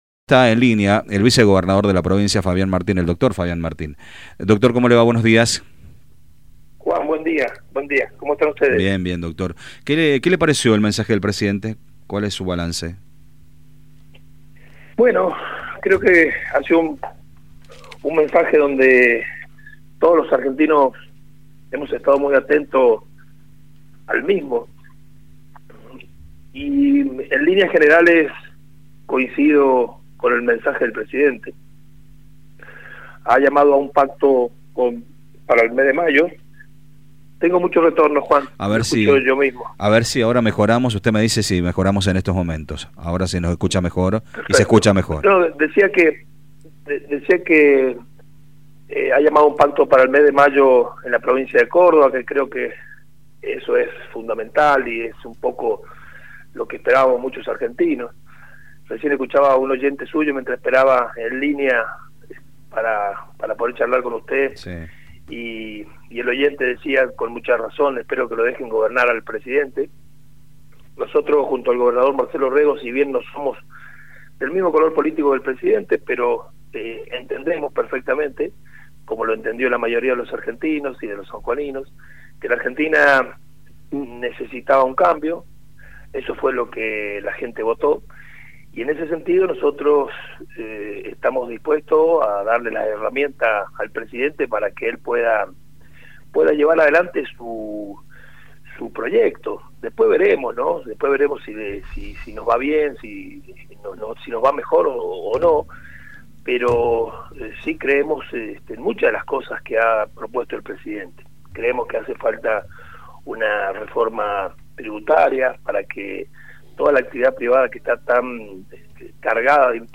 Para referirse al tema, el vicegobernador Fabián Martín mantuvo una comunicación con radio Sarmiento y destacó que en los últimos cuatro años no se había registrado un paro docente, lo que evidencia una situación desigual en comparación con el presente.